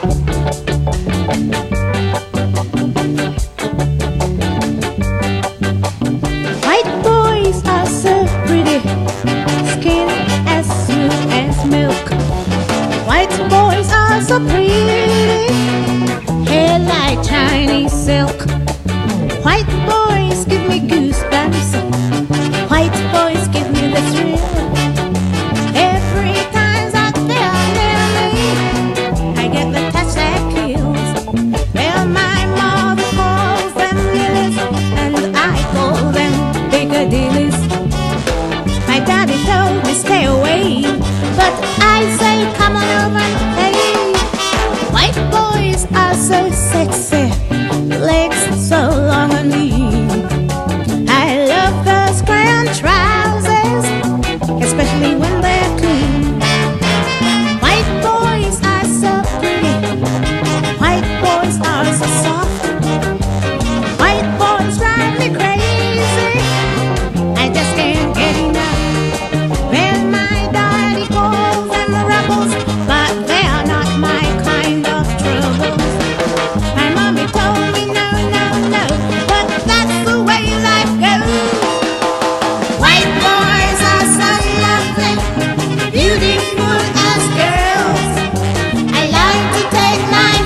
WORLD / REGGAE / SKA/ROCKSTEADY / NEO SKA / NEW WAVE (UK)